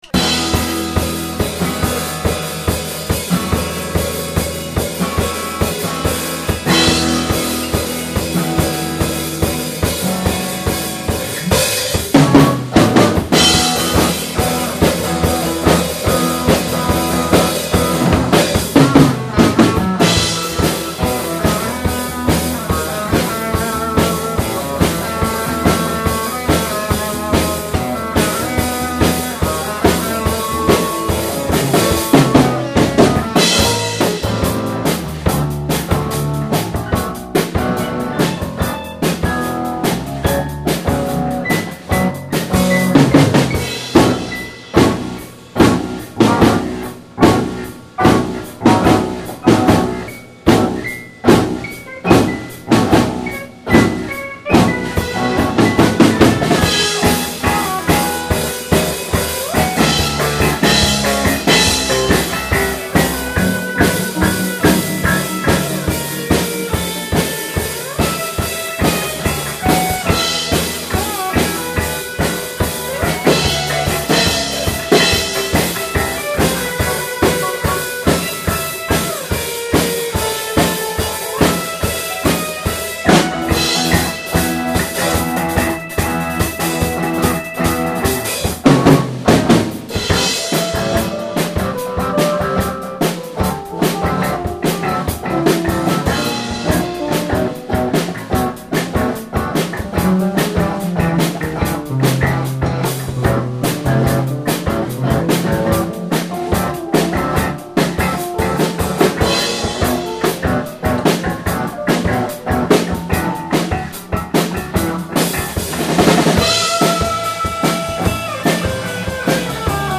SHINODA BAND - studio session (1988)
一回きりのスタジオ練習でした。